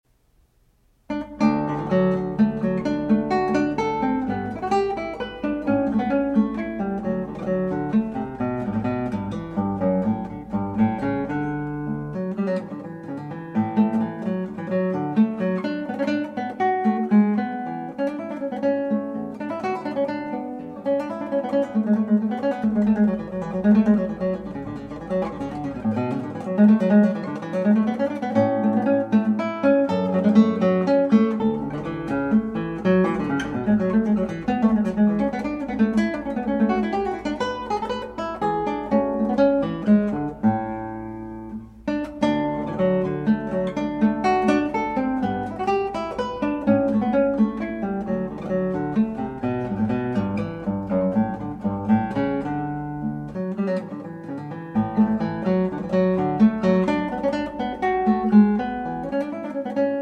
guitar
transcribed for guitar